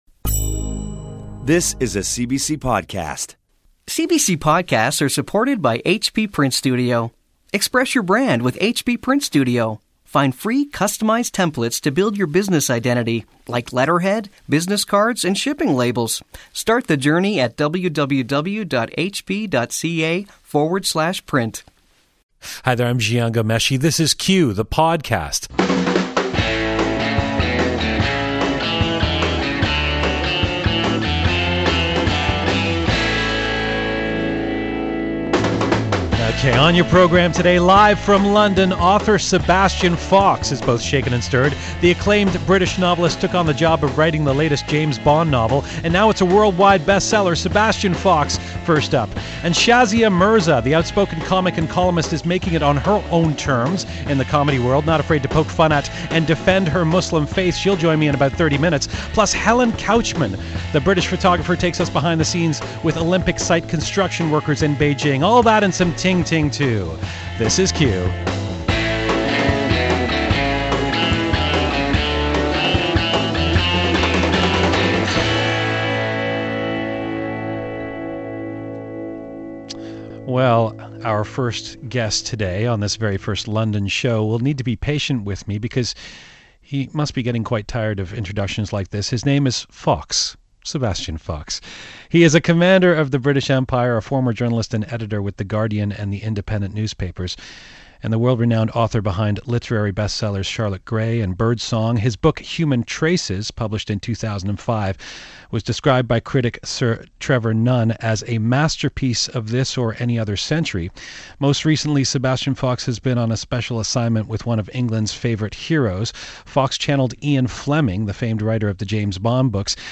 Published 2008 ORDER BOOKS HERE Interview for BBC Radio Canada Review of WORKERS in The New Yorker Slideshow about the WORKERS project for The Age